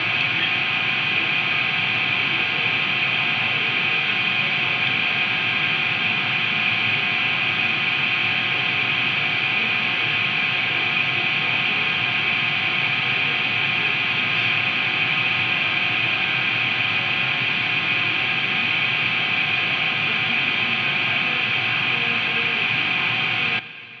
Ich habe mir die Mühe gemacht um mal dazustellen wie sich sein Rauschen sehr wahscheinlich anhört, und habe dieses höllische Rauschen aufgenommen und ne mp3 gemacht .
Anhänge Exzessives Rauschen.mp3 942,1 KB